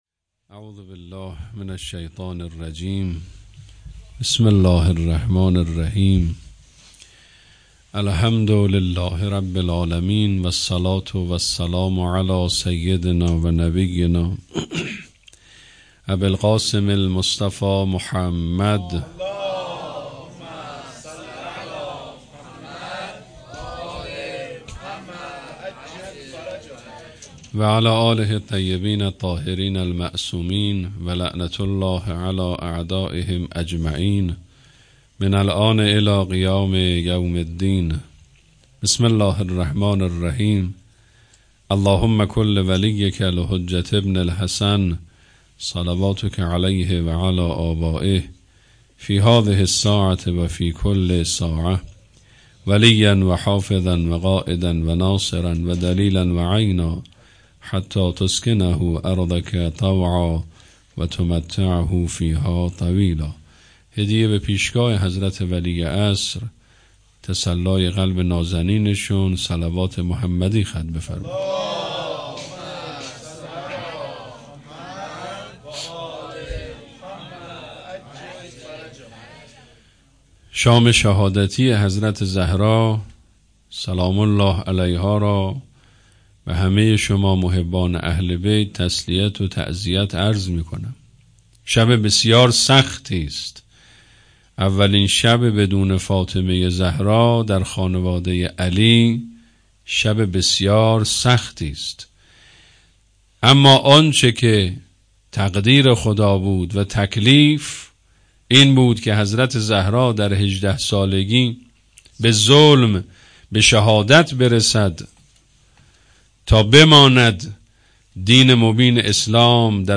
اقامه عزای شهادت حضرت زهرا سلام الله علیها _ دهه دوم فاطمیه _ شب سوم